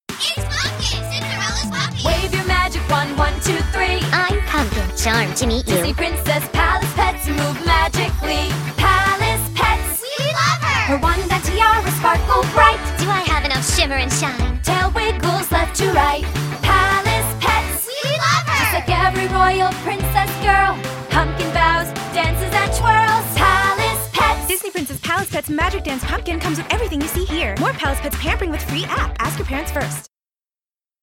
Children's